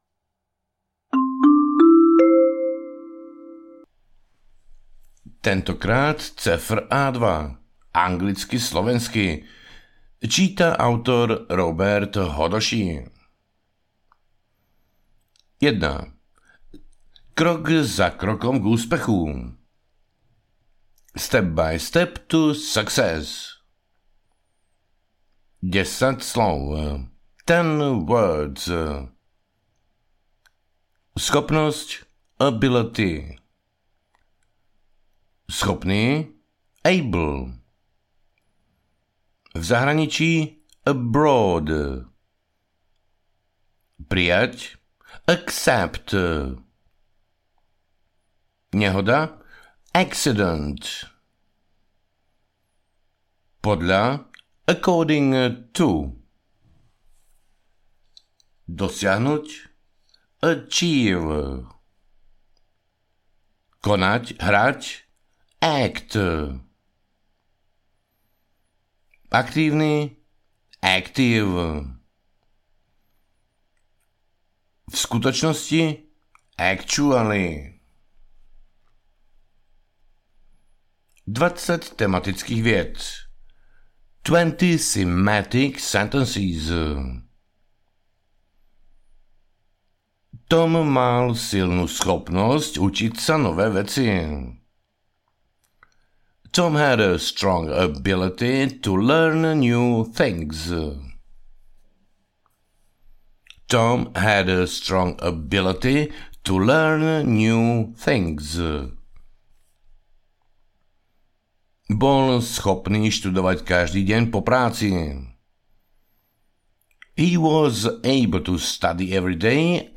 Tentokrát CEFR – A2, anglicky-slovensky audiokniha
Ukázka z knihy